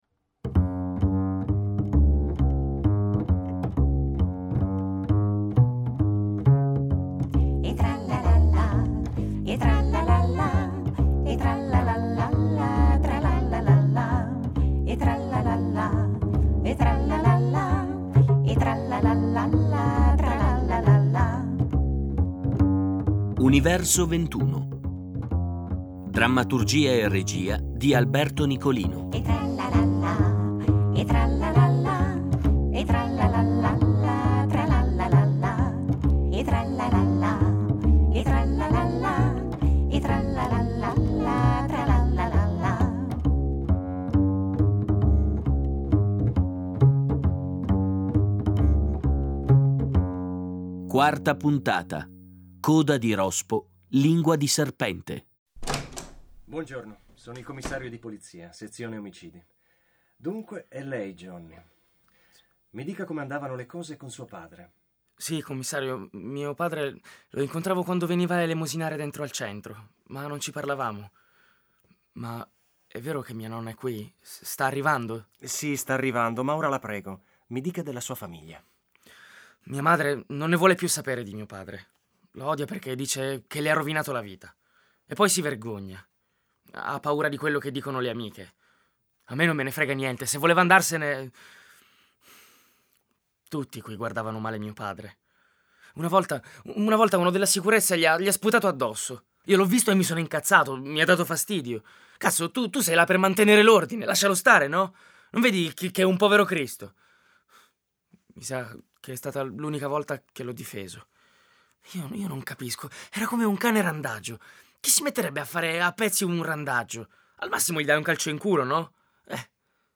Radiodramma (puntata4) | Fiaba e Narrazioni